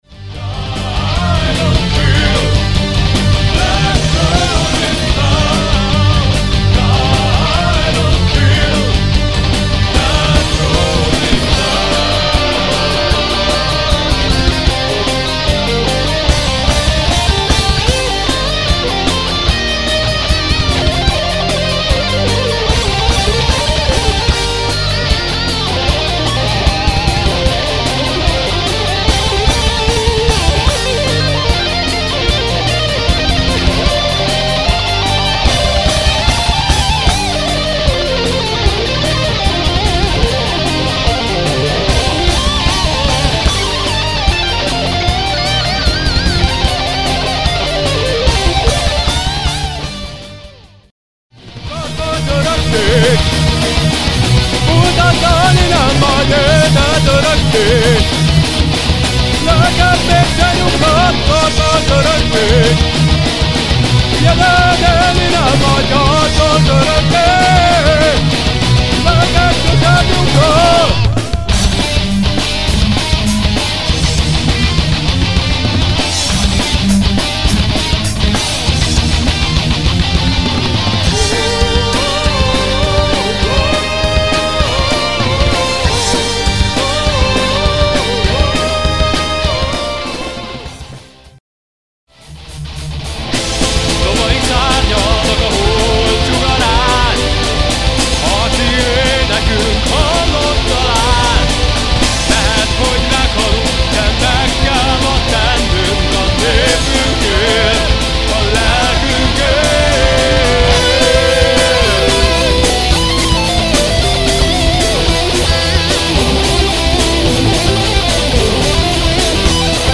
szólógitár
billentyű
basszusgitár